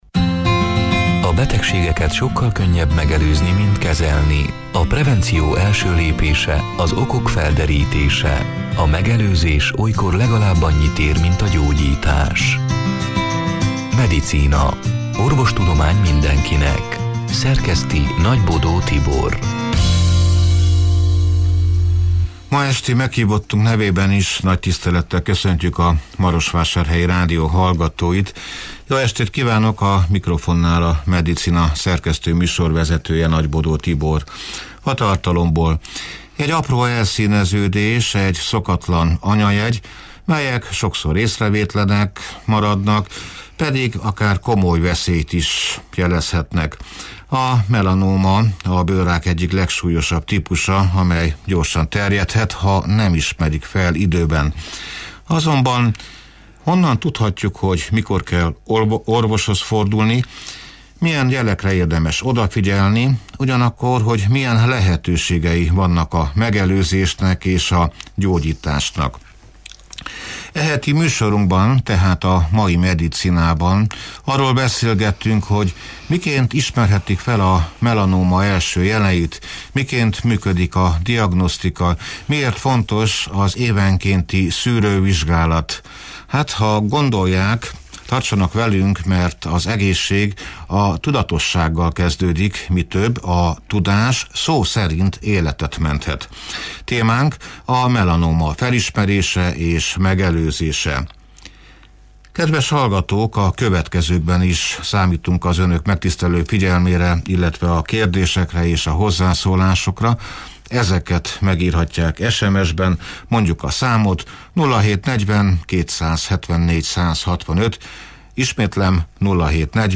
(elhangzott: elhangzott: 2025. május 21-én, szerdán este nyolc órától élőben)